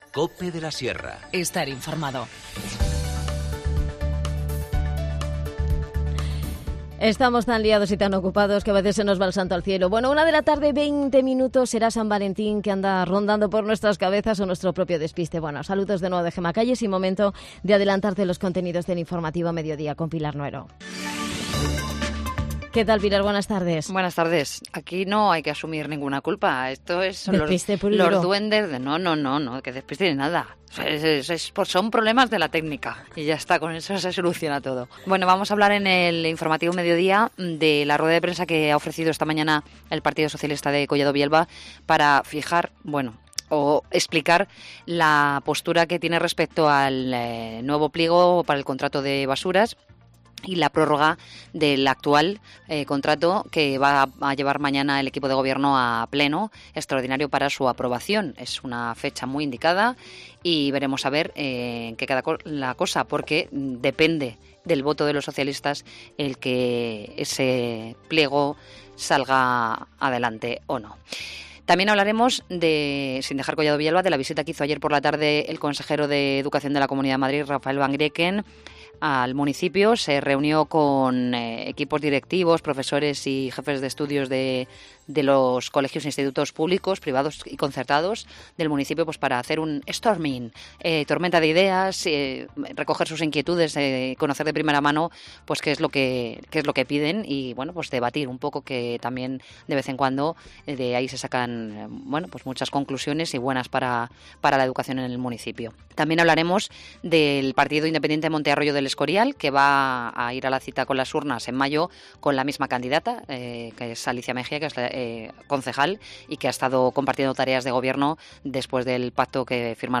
A punto de iniciarse la IV Edición de La Muestra de Cine Efímero en Moralzarzal hablamos con Carlos Iglesias, actor, director y alma mater de esta iniciativa.